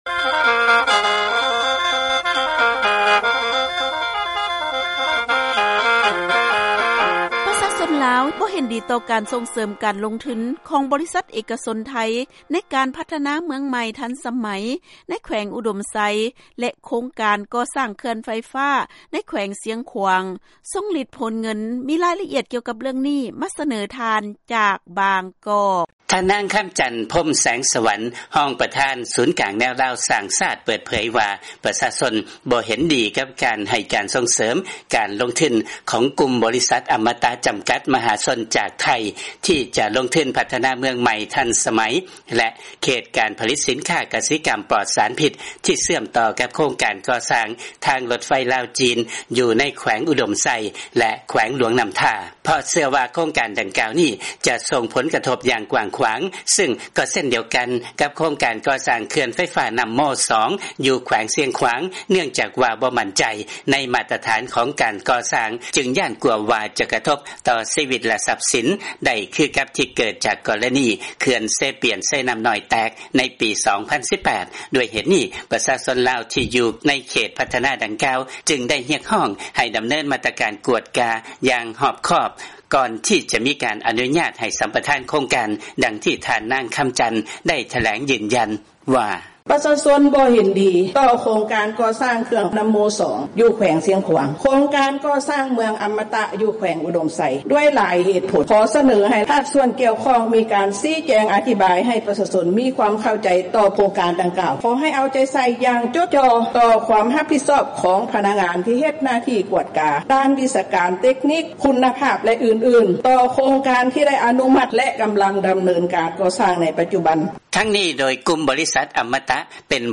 ເຊີນຟັງລາຍງານ ປະຊາຊົນ ລາວ ບໍ່ເຫັນດີກັບ ໂຄງການ ເມືອງໃໝ່ທັນສະໄໝ ແລະ ການສ້າງເຂື່ອນ ໃນແຂວງອຸດົມໄຊ ແລະ ຊຽງຂວາງ